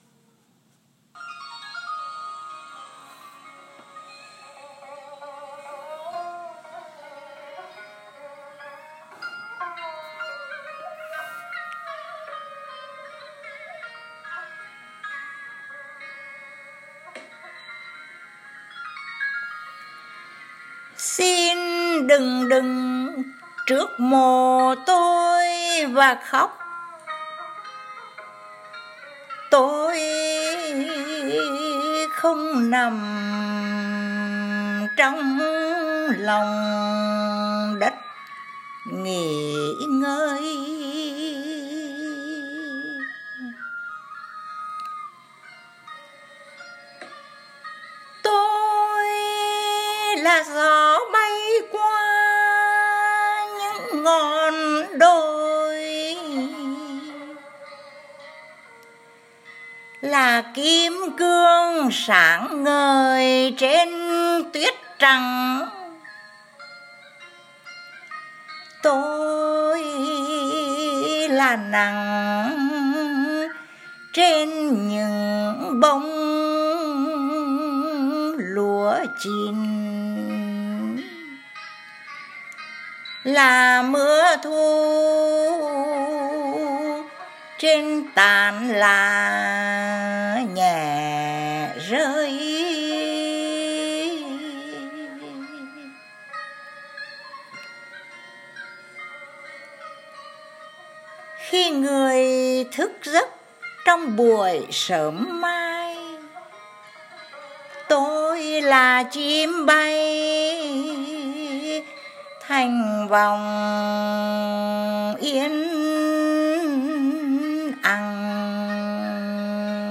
Poetry Reading
Đọc Thơ 3 bình luận
giọng Huế làm chị nhớ nhà kinh khủng, nhất là khi Tết sắp đến.